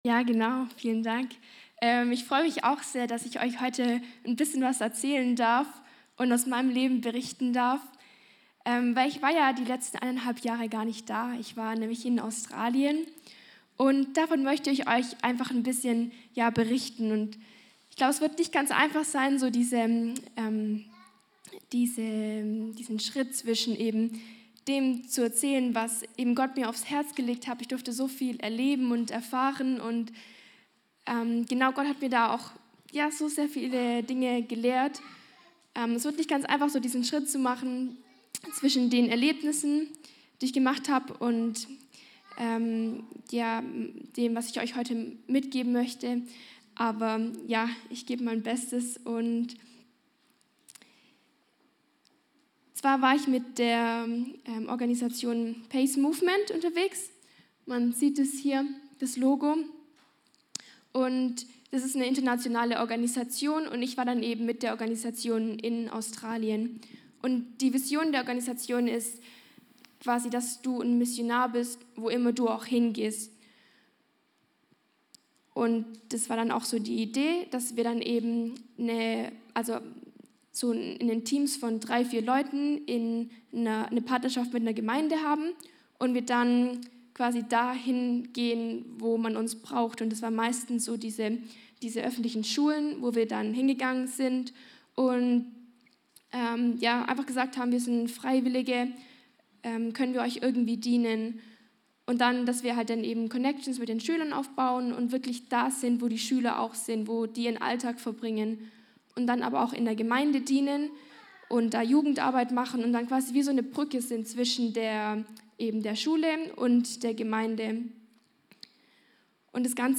Dienstart: Gottesdienst Gottesdienst